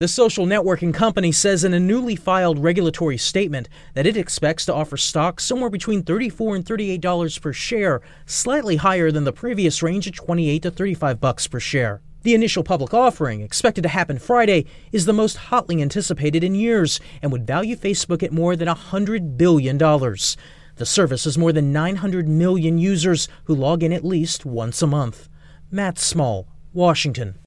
Facebook is raising the price at which it plans to sell stock to the public. AP correspondent